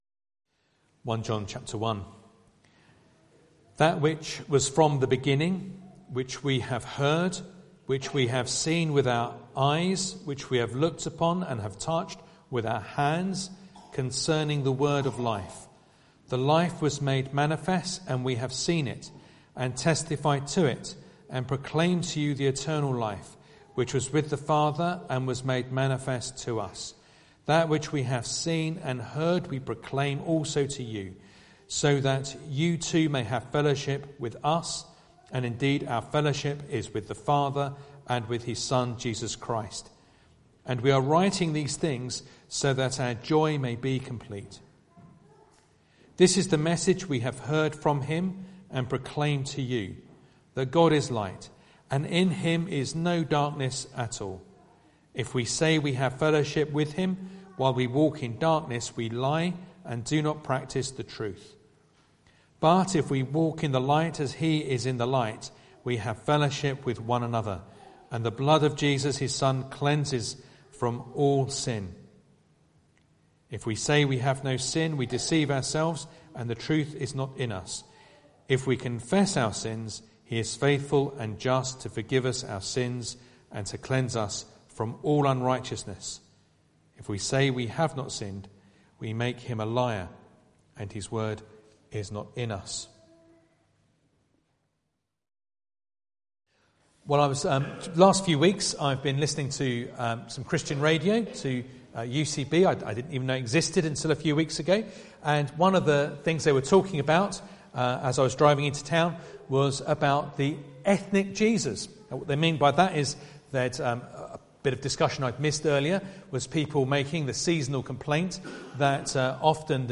1 John 1 Service Type: Sunday Morning Bible Text